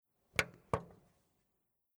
Television Philips Discoverer, Mod. 14GR1220/22B
Operate menu
24857_Menue_bedienen.mp3